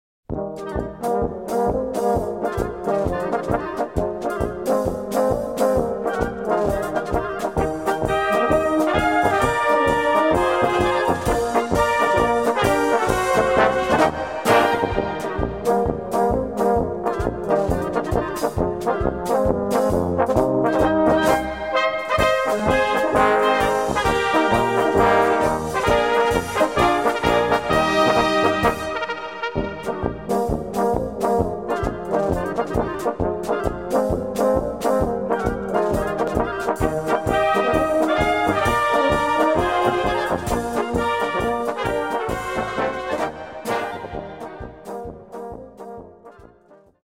Gattung: Mährische Polka
Besetzung: Blasorchester